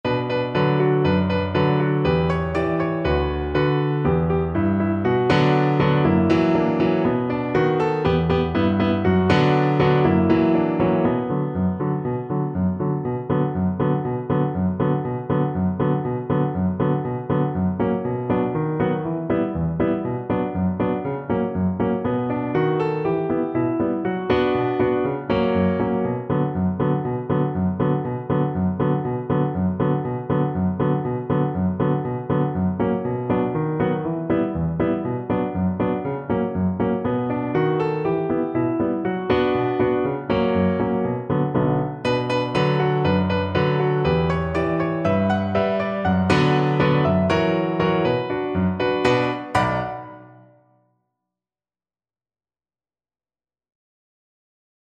Allegro (View more music marked Allegro)
2/4 (View more 2/4 Music)